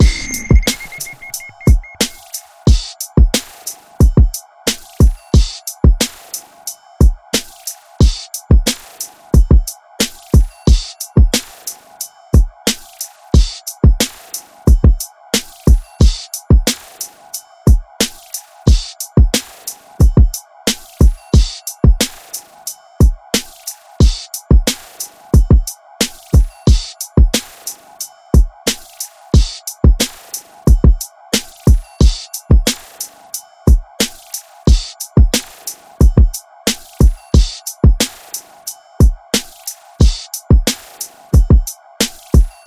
100个大鼓
Tag: 90 bpm Hip Hop Loops Drum Loops 7.18 MB wav Key : Unknown Ableton Live